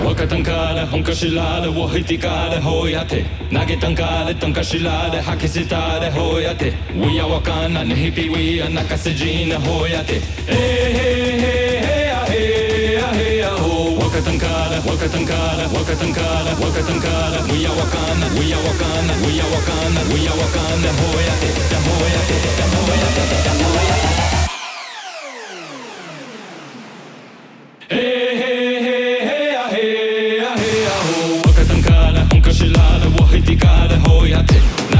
Жанр: Транс